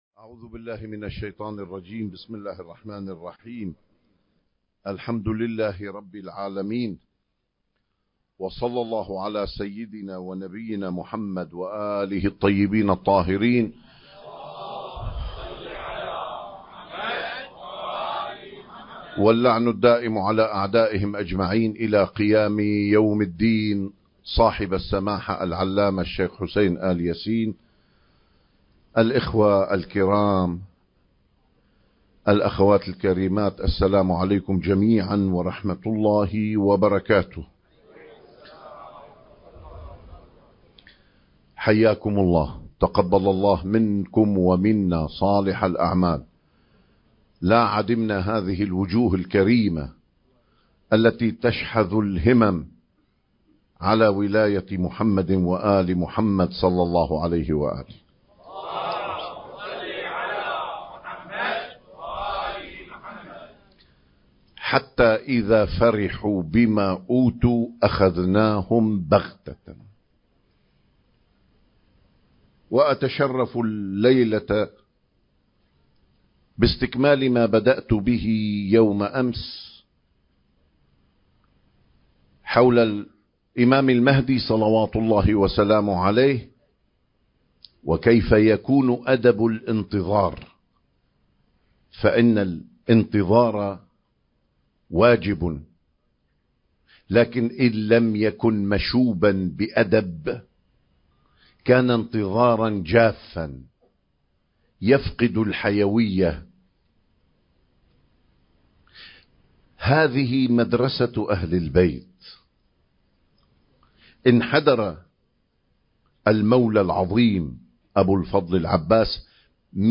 الإمام المهدي (عجّل الله فرجه) وأدب الانتظار (2) المكان: مسجد آل ياسين / الكاظمية المقدسة التاريخ: 2025